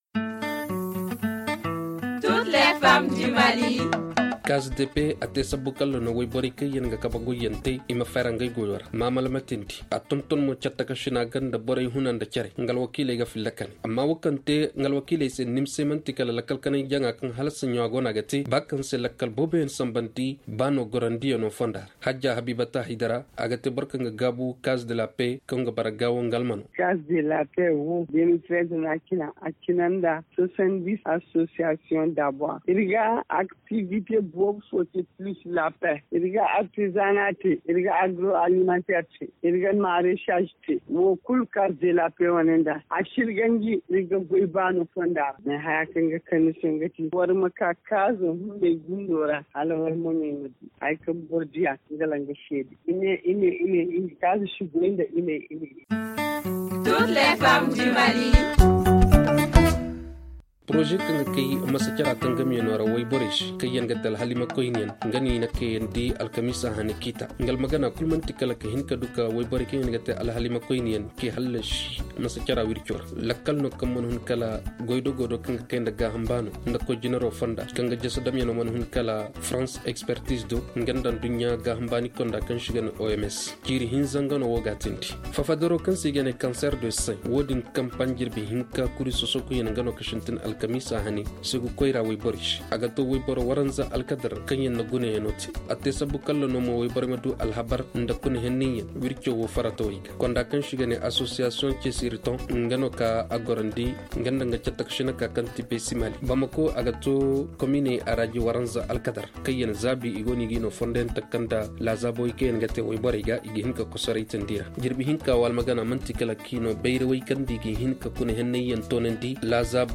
Le magazine « Toutes les femmes du Mali », s’intéresse à cette question.